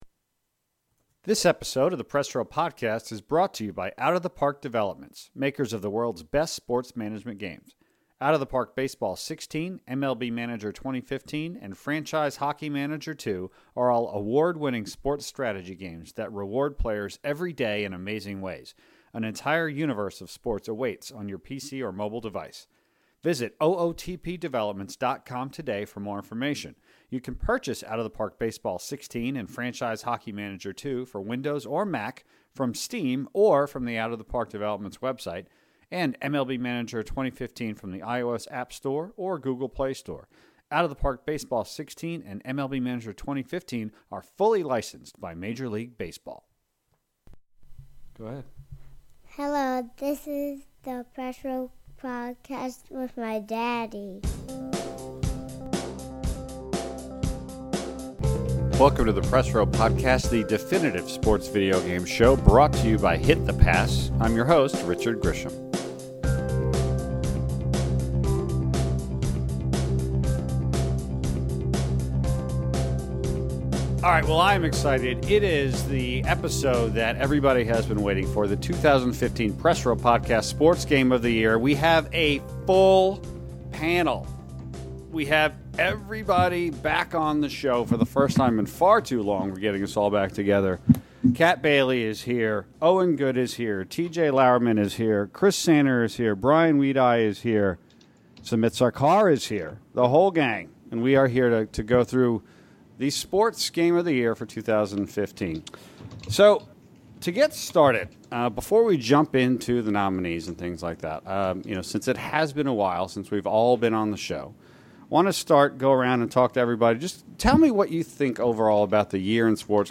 The entire panel gets together to state their cases for each of the biggest and best titles to be named Press Row Podcast Sports Game of the Year for 2015.